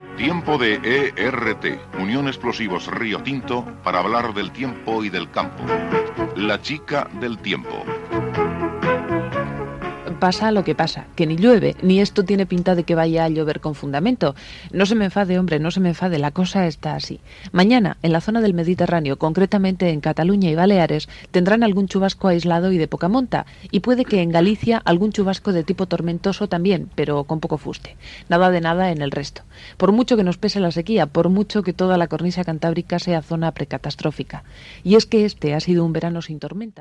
Secció "La chica del tiempo" Careta i previsió del temps
Informatiu